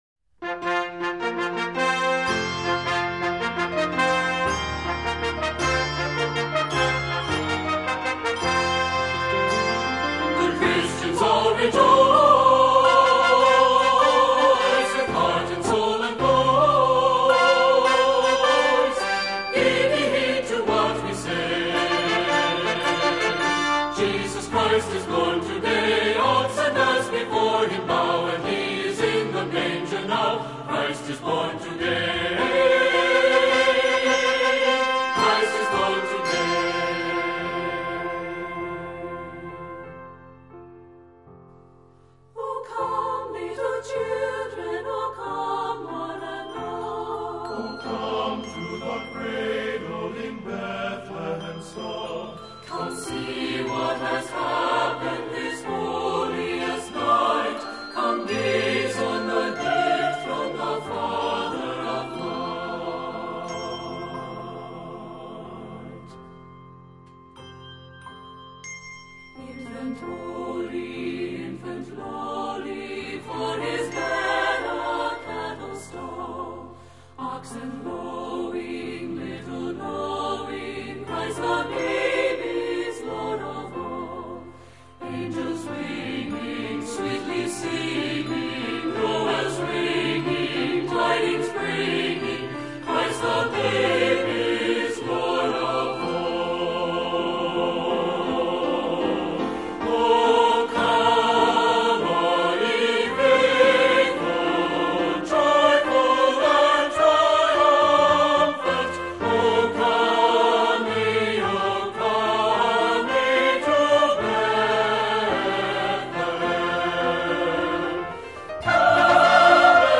Octaves: 3
Varies by Piece Season: Christmas